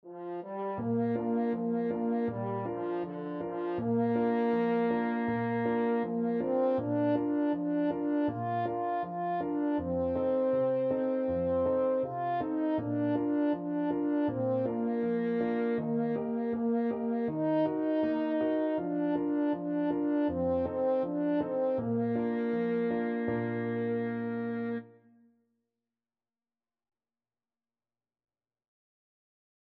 French Horn
Traditional Music of unknown author.
Bb major (Sounding Pitch) F major (French Horn in F) (View more Bb major Music for French Horn )
4/4 (View more 4/4 Music)
Traditional (View more Traditional French Horn Music)